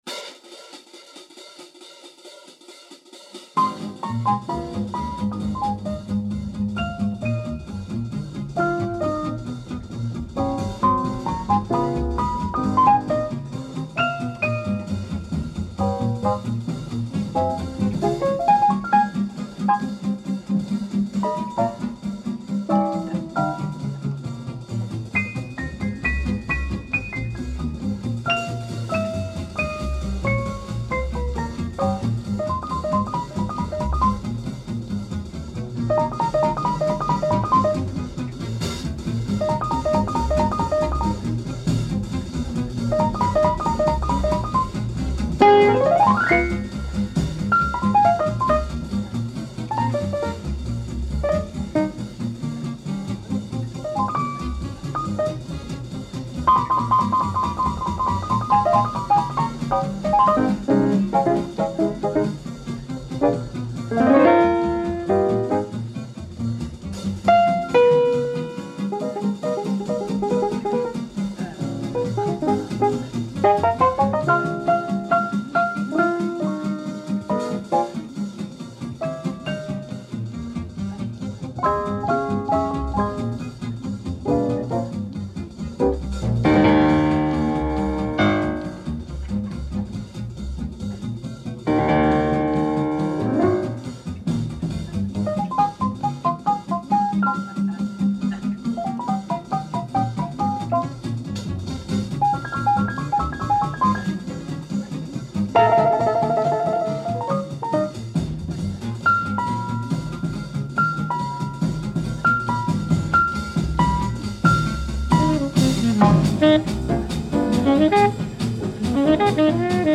Alto Saxophone
Clarinet
Tenor Saxophone